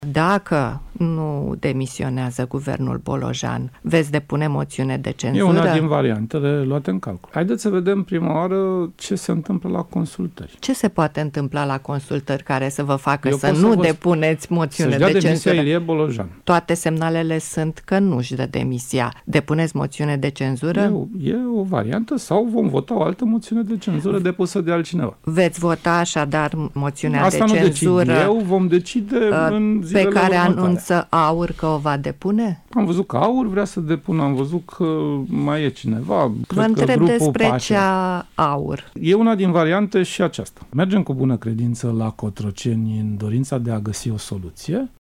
„PSD va merge la Cotroceni cu bună credință”, a spus liderul social-democraților într-un interviu acordat Radio România Actualități.